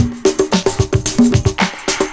112PERCS02.wav